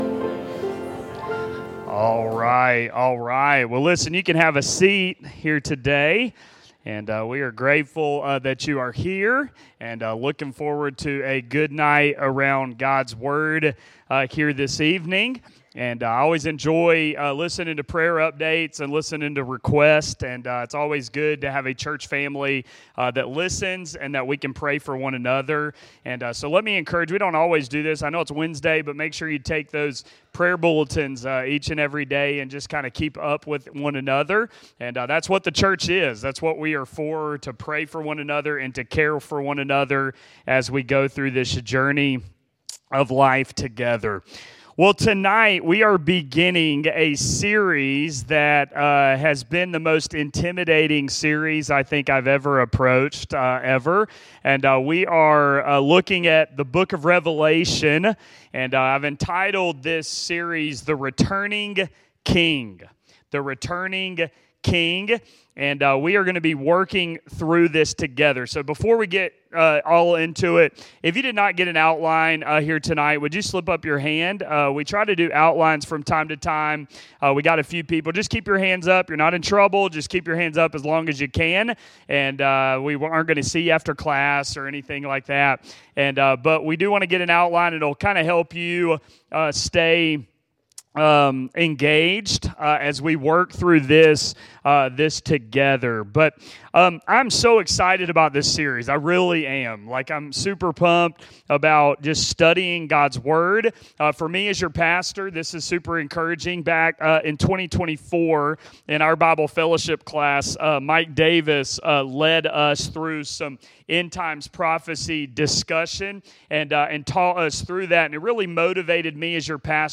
This sermon is more of an intro to the book, but he does start the expositional study through Revelation 1:1-11.